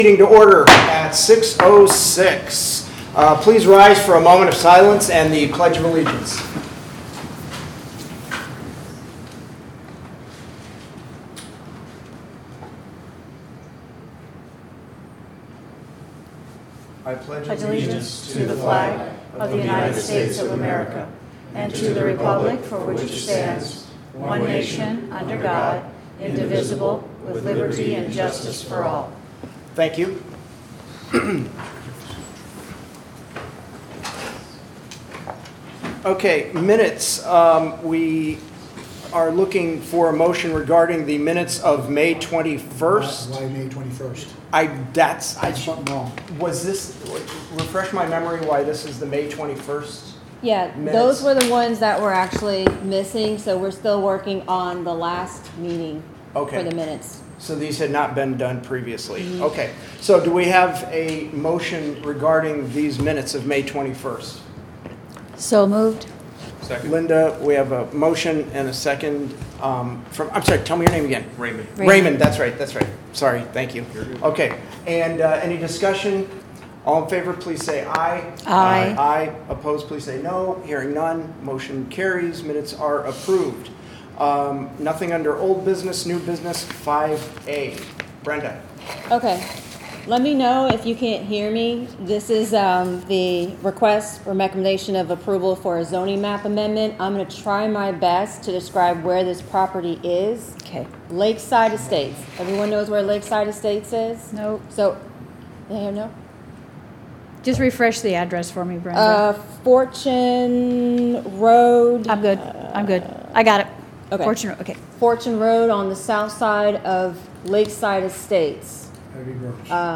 However, as of this writing, the Kissimmee PAB Meeting from 2025-11-19 has not had its recording uploaded.